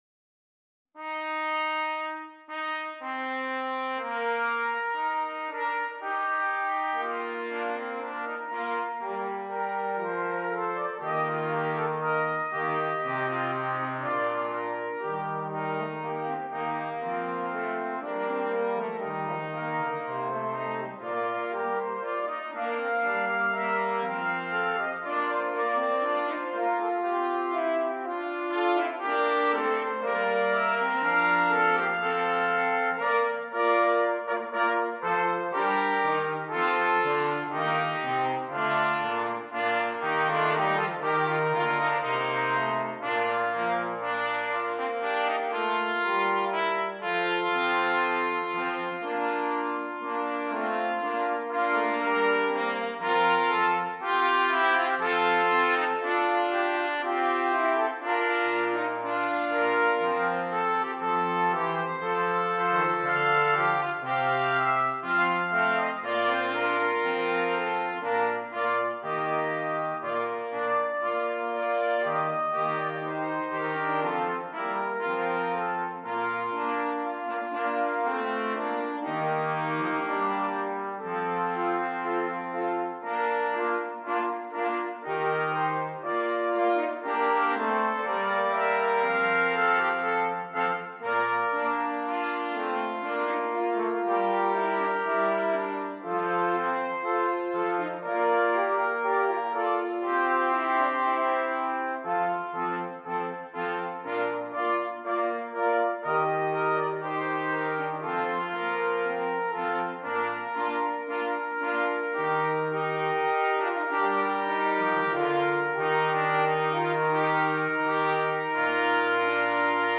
Brass Quartet
Alternate parts: Trombone (Horn)